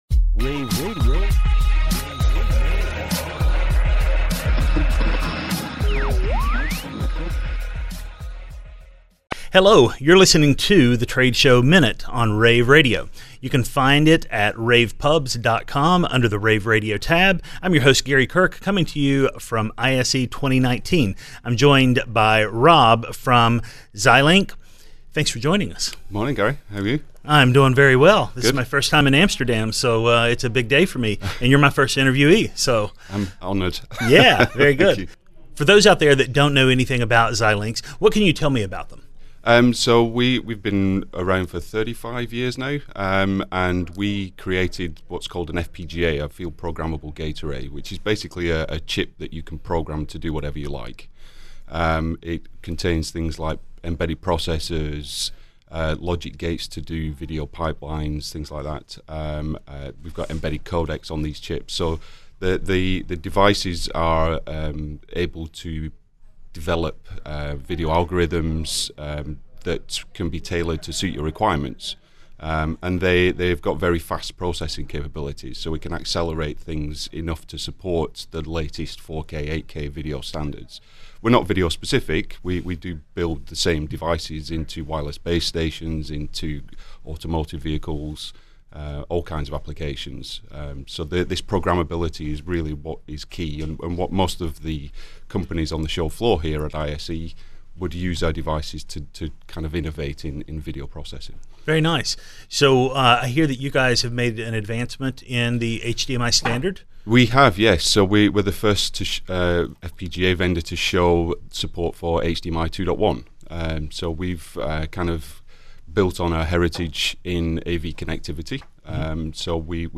February 5, 2019 - ISE, ISE Radio, Radio, rAVe [PUBS], The Trade Show Minute,